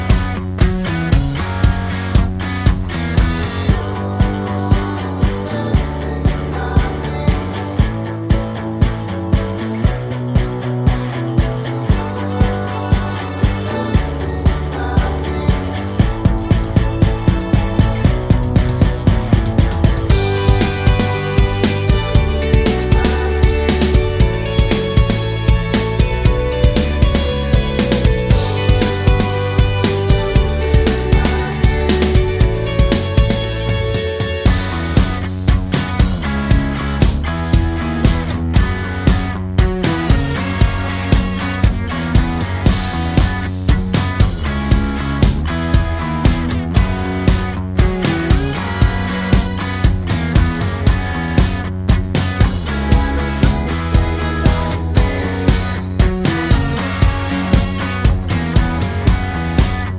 no Backing Vocals Indie / Alternative 3:47 Buy £1.50